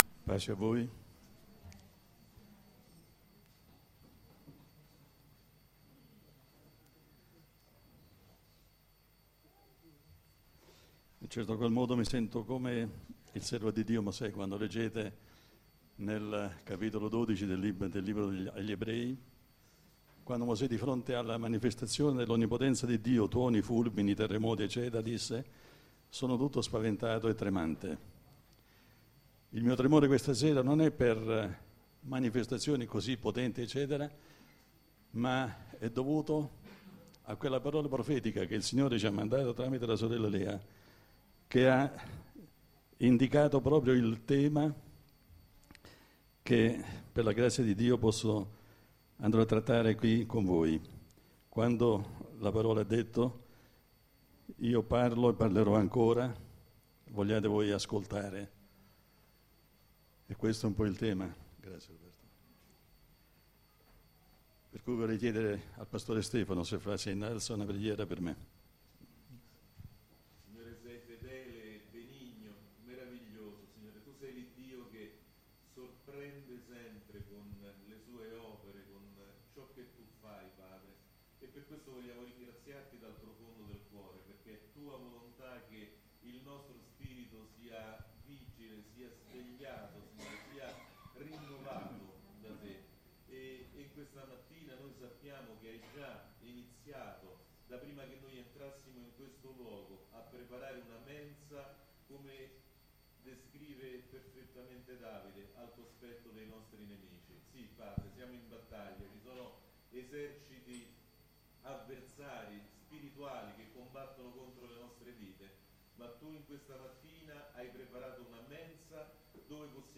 Sermoni della domenica